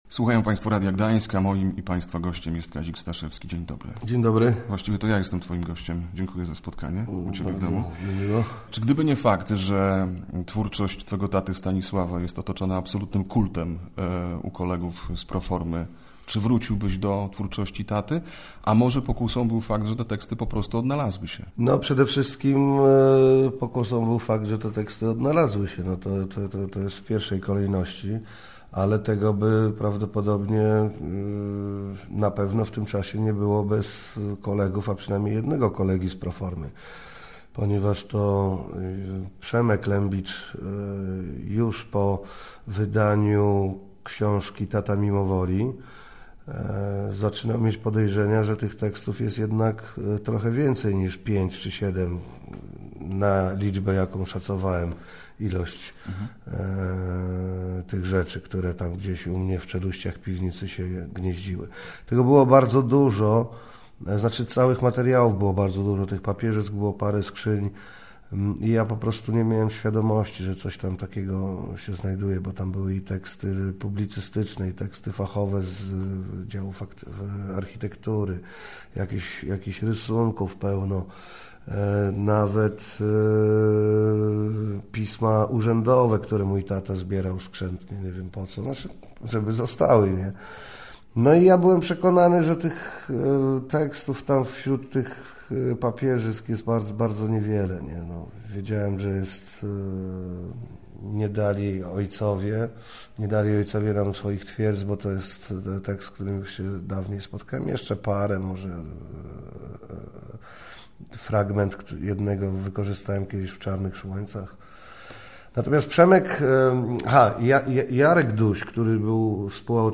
na antenie Radia Gdańsk